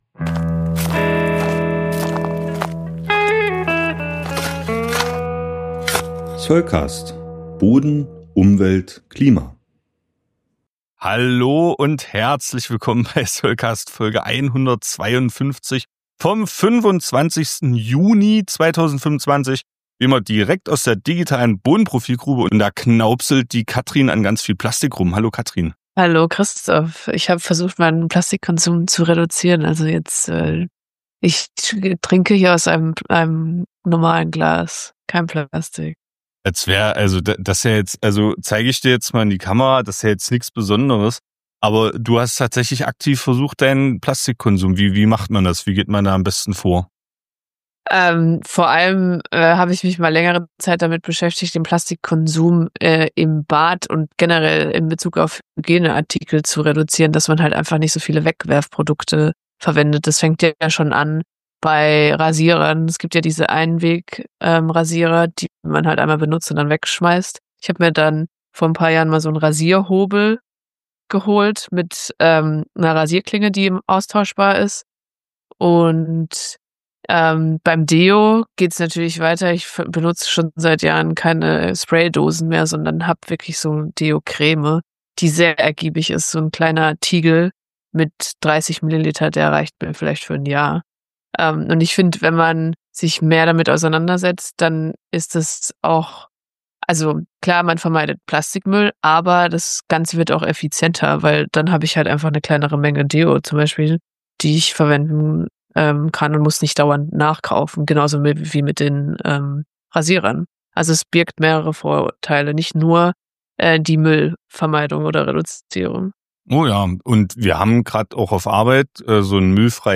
SC145 Interview: Heute schon abgepflastert?